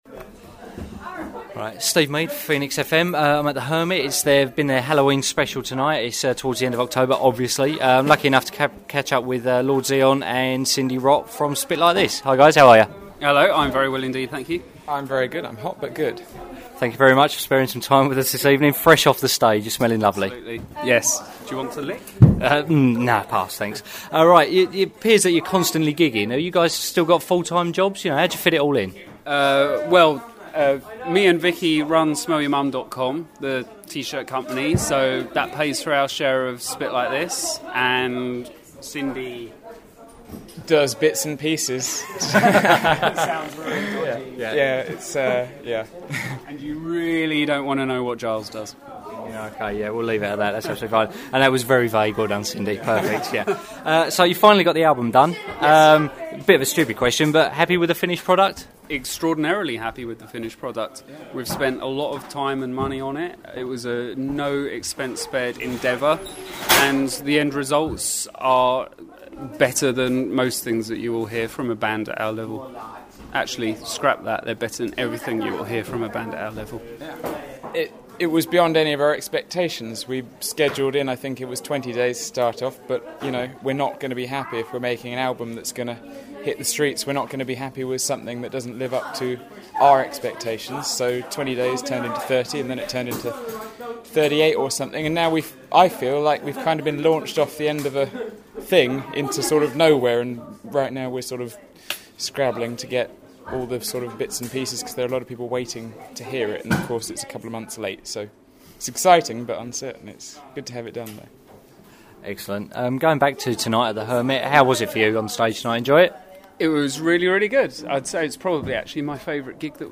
Interview/Article: Interview
Where: The Hermit, Brentwood, Essex after our gig there